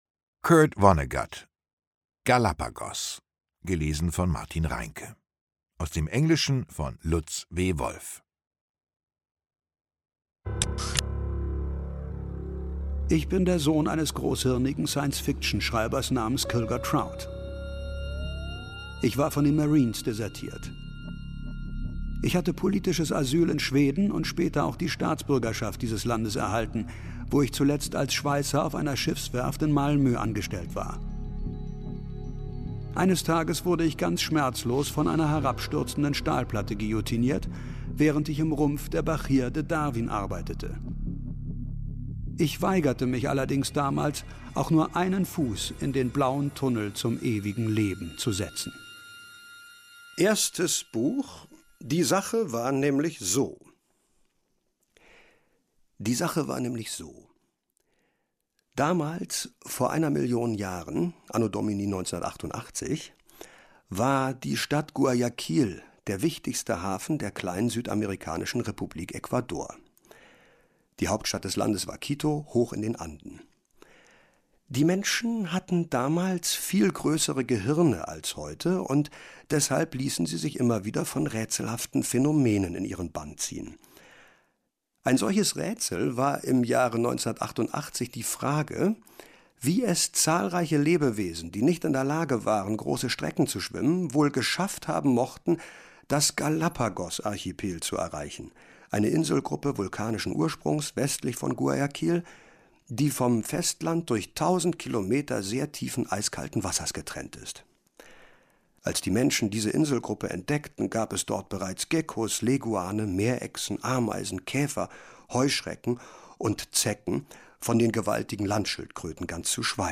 Lesung